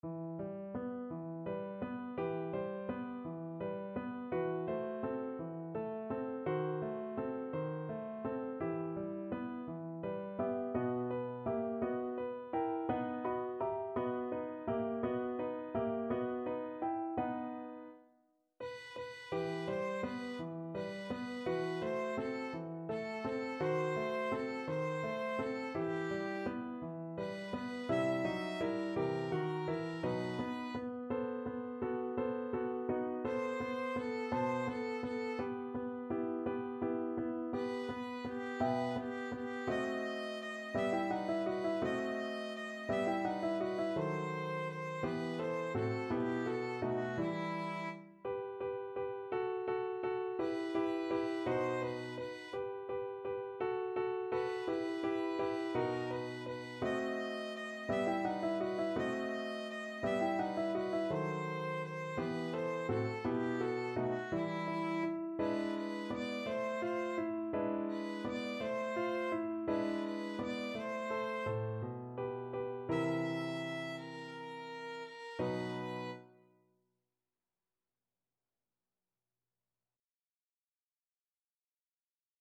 Violin
E minor (Sounding Pitch) (View more E minor Music for Violin )
6/8 (View more 6/8 Music)
. = 56 Andante
Classical (View more Classical Violin Music)
lho_perduta_VLN.mp3